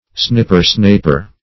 Snipper-snaper \Snip"per-snap`er\, n. A small, insignificant fellow.